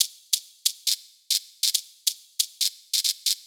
Grit Shaker 138bpm.wav